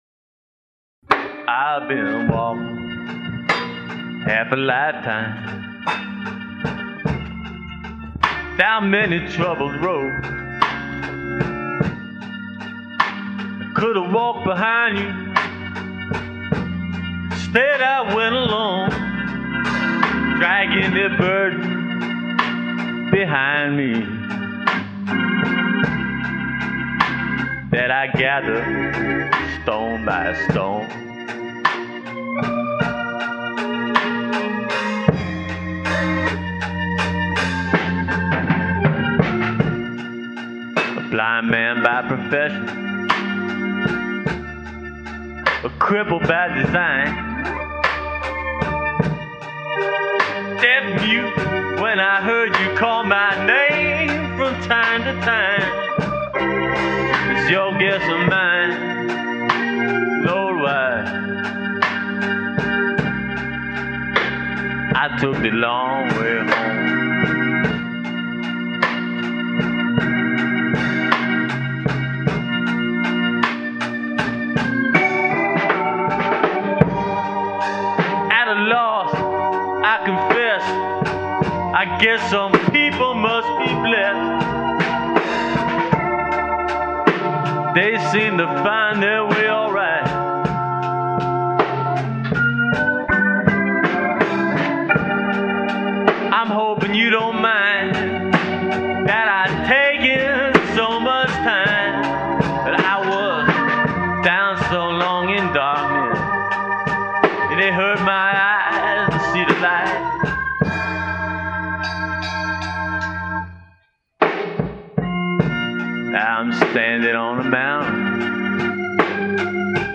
Recorded in the early 1980's in a chicken coop on the outskirts of town, these were some early interpretations that attempted to capture the spirit of Leroy Carr and Scrapper Blackwell, as well as the "churchy feel" music that is so predominant in the Memphis and Mississippi Delta area.